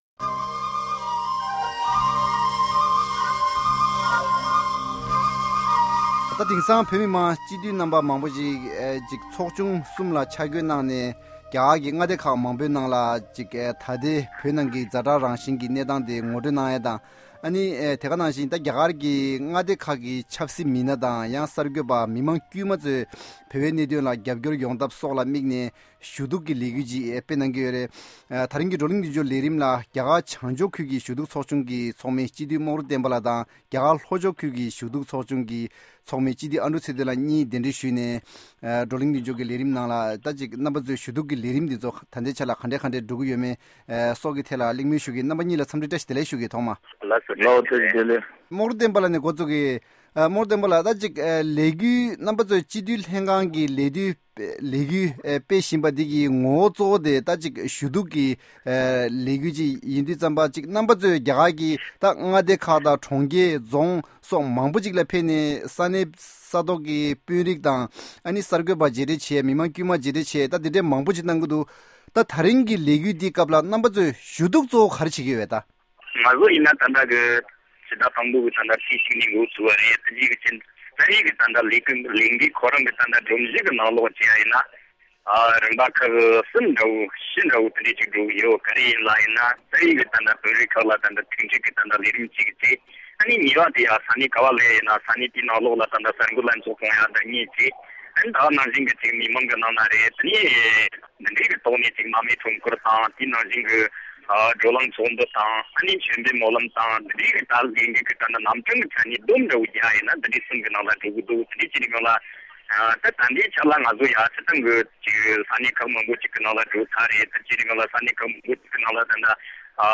In view of the ongoing worsening situation inside Tibet, the Tibetan Parliament-in-Exile is currently undertaking a month-long all-India lobbying campaign to garner wider support for Tibet’s cause. Table Talk invites north-zone delegation member Chitue Mogru Tenpa and south- zone delegation member Chitue Adruk Tsetan to discuss the significance and impacts of their lobbying efforts.